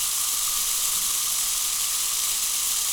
gas_leak_01_loop.wav